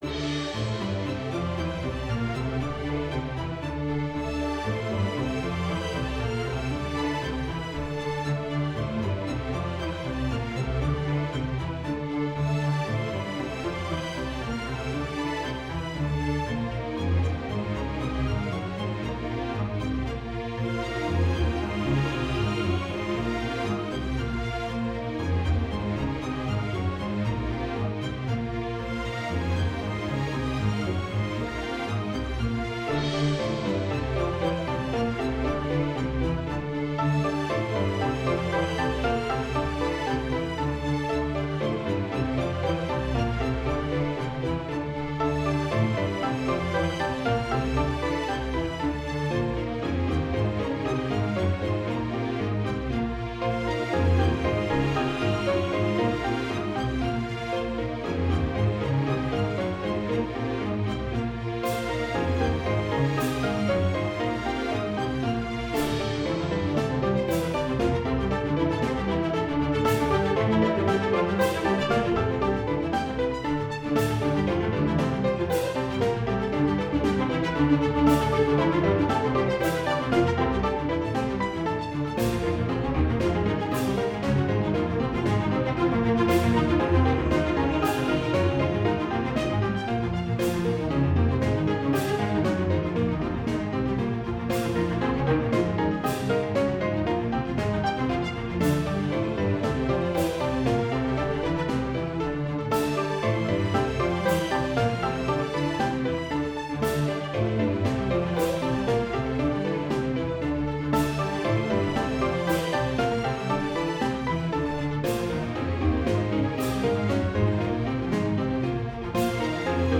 Here is some EPIC Orchestral VGM~.
Game Music
epic orchestral battle